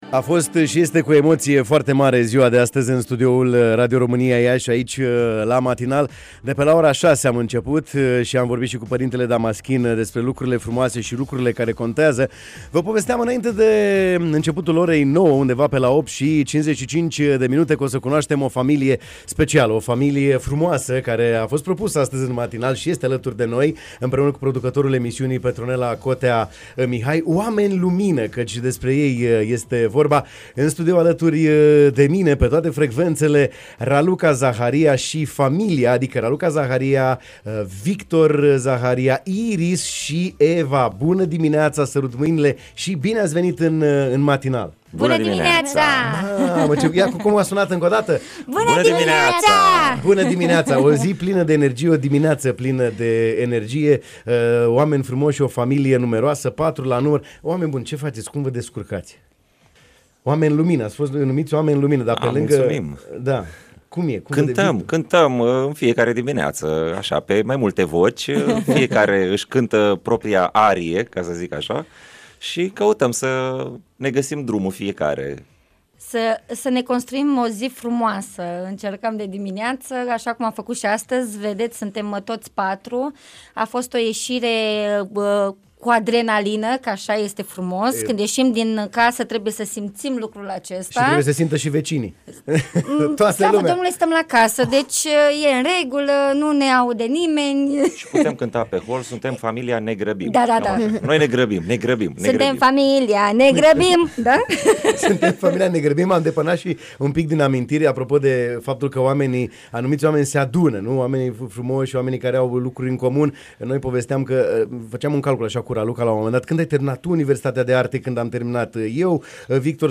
13 aprilie 2023, ORA 9.15 – RADIO ROMÂNIA IAȘI EMISIUNEA „BUNĂ DIMINEAȚA”! OAMENI LUMINĂ, ÎN DIRECT, LA RADIO IAȘI !